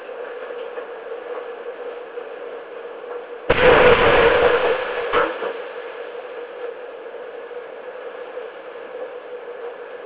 A test deployment was conducted at a longwall coal mine in the United States.
Examples of the audio recorded by the DAS microphones during these events are included below.
In these recordings you can hear the hum of the longwall machinery followed by a crashing noise as material is ejected onto the face. While these events weren't particularly hazardous, they do represent a smaller version of a rockburst.
The lead cables (blue) tend to record shorter signals that are likely more representative of the released seismic energy while the microphones (orange) record longer signals on a few channels closest to the events, picking up the sounds of the material being ejected and settling.